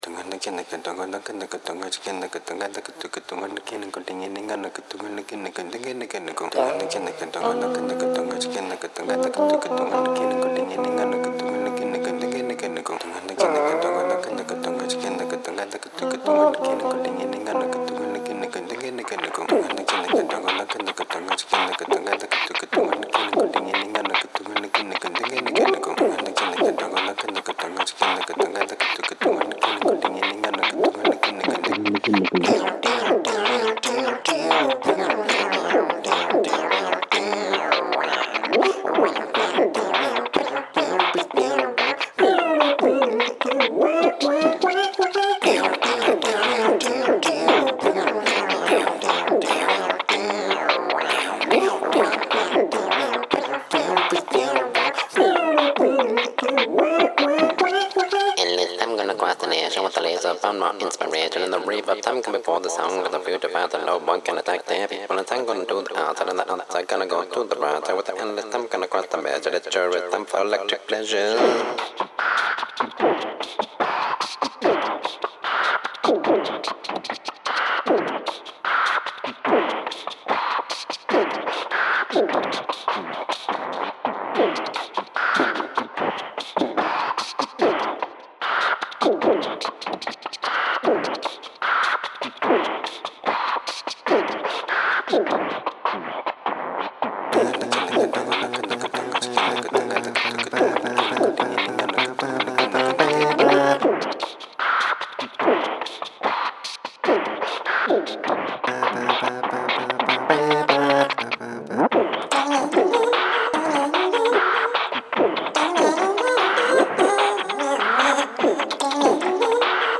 loved the pitched voices